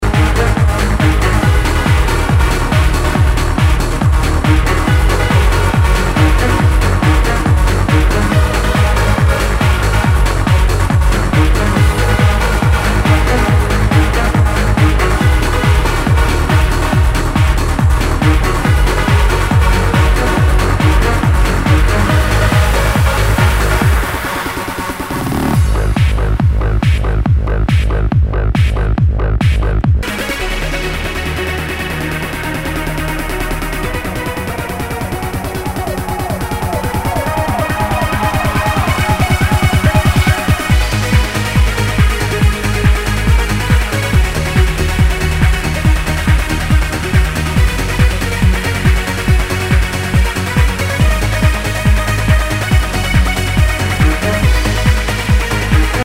HOUSE/TECHNO/ELECTRO
ハード・トランス！